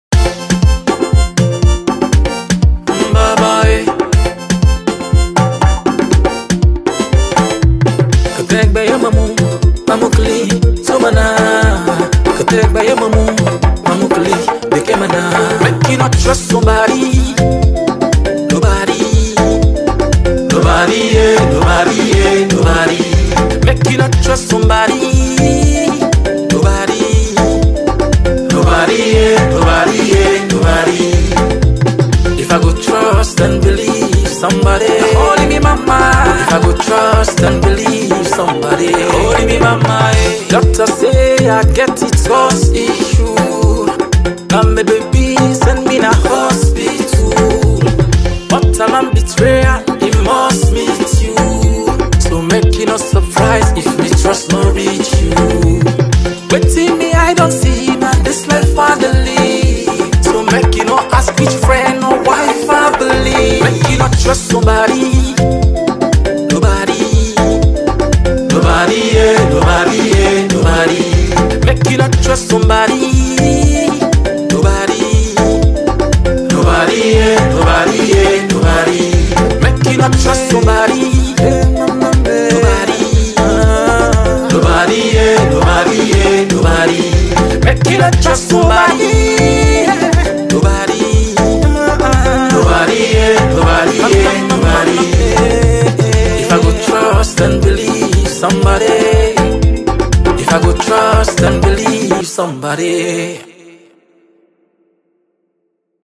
The sound is deep, emotional, and packed with pure talent.